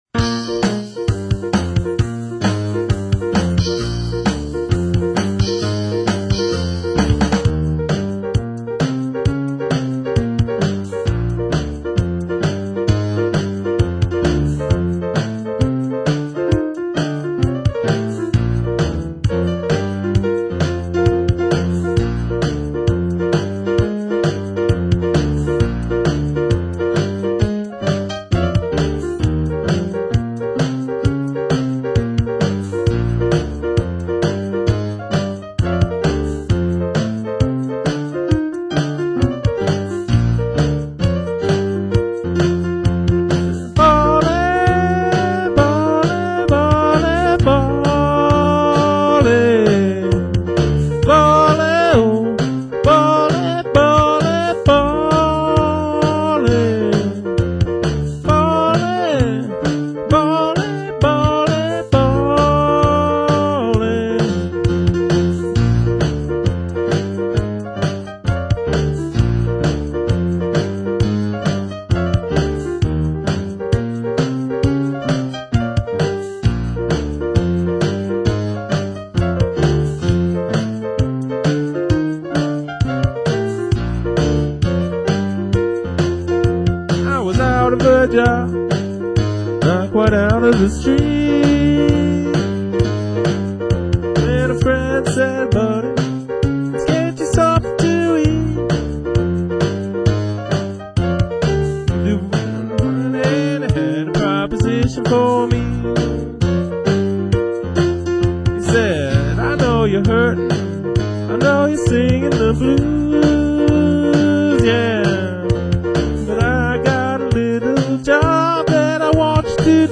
audio link Here's a shuffle in Bb played at 132 bpm.